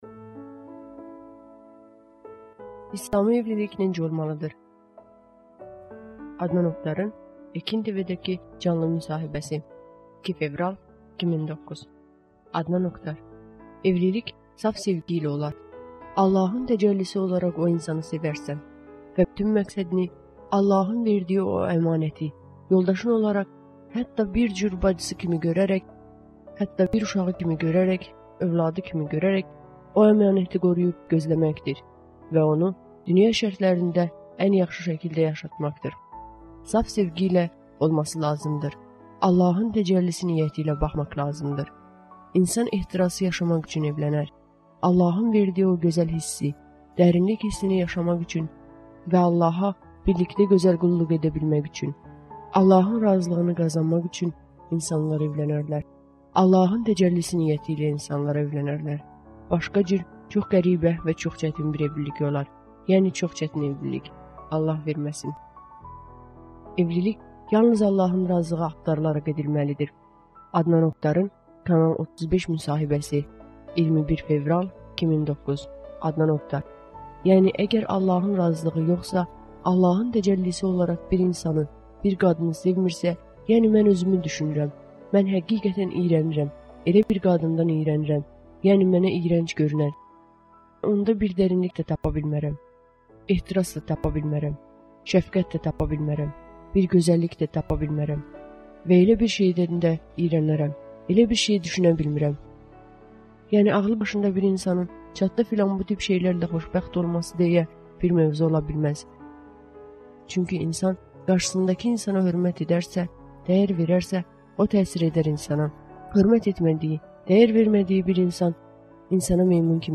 (Cənab Adnan Oktarın Ekin TV-dəki reportajı, 2 fevral, 2009) Adnan Oktar: ...